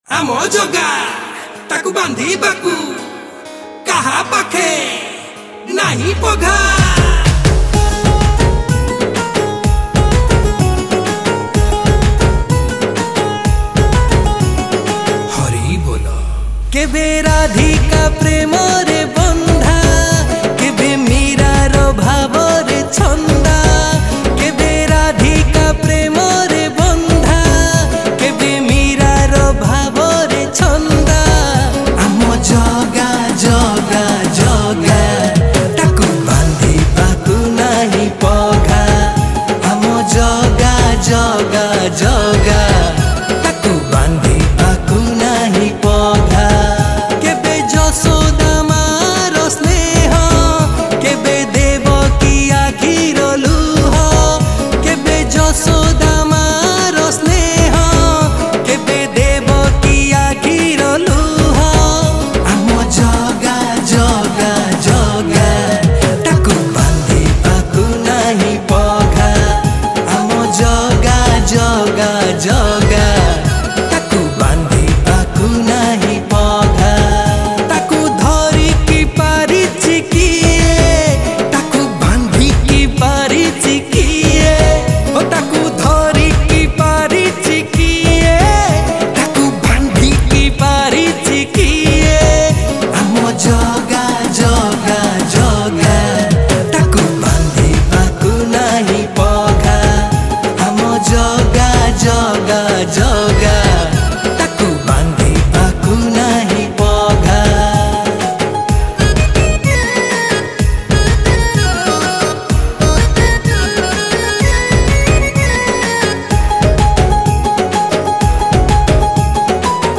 Odia Bhajan Song 2025 Songs Download